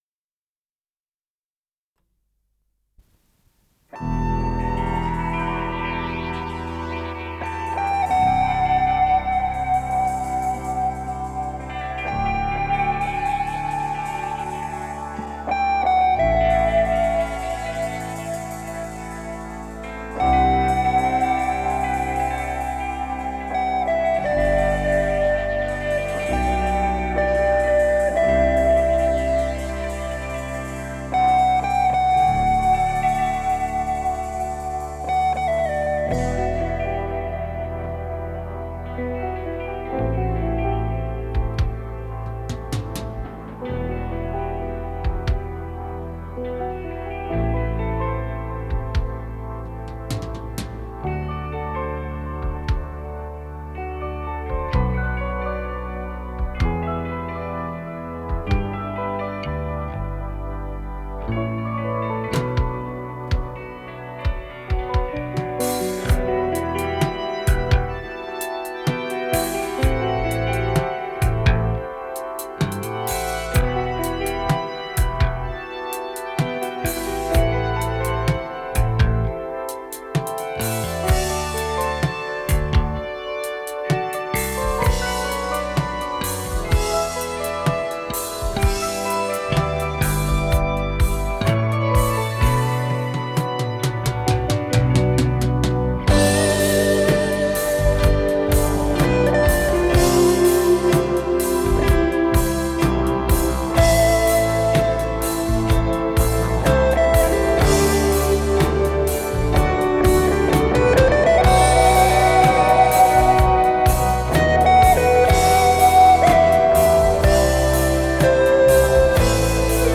с профессиональной магнитной ленты
ПодзаголовокИнструментальная пьеса
Скорость ленты38 см/с
ВариантДубль моно